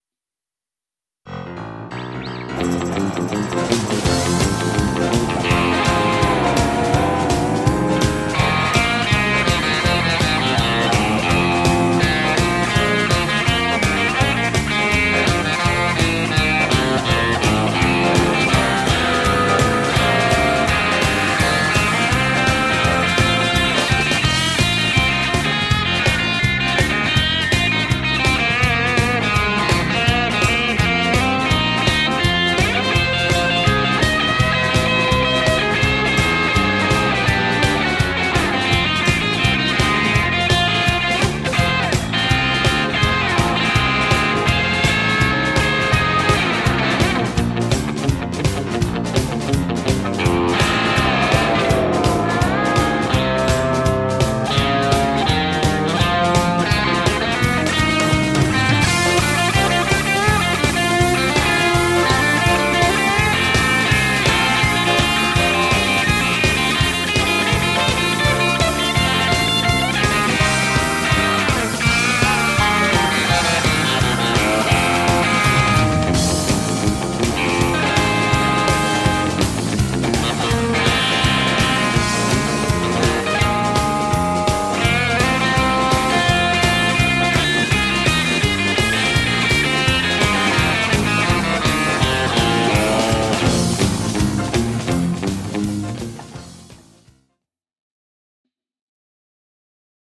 Hauska perinnerock-henkinen revittelevä meininki.
paras meno 3 p
rokkapilliosastoa Setzer- tyyppisesti, ehkä rytmiikka vois olla paikoin tarkempaa, muuten toimii kyllä, tosi hyvä soundi